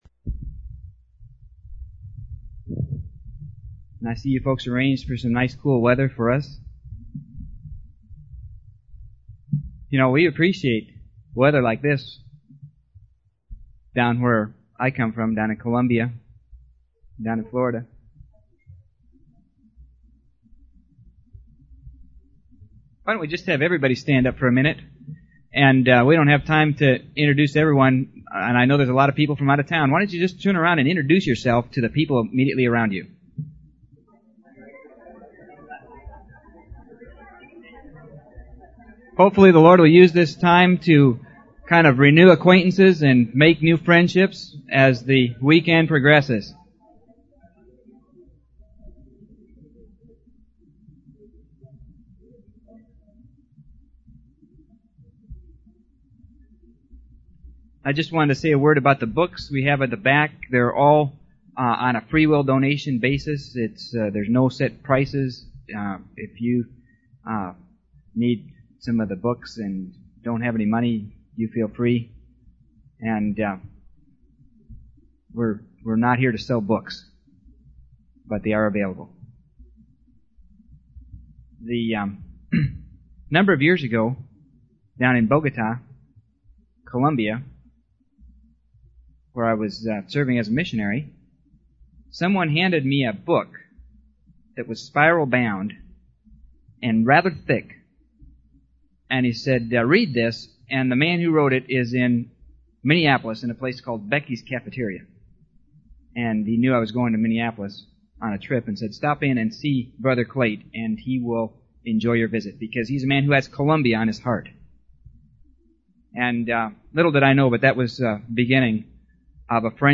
In this sermon, the speaker emphasizes the importance of getting rid of dead works in order to overcome challenges.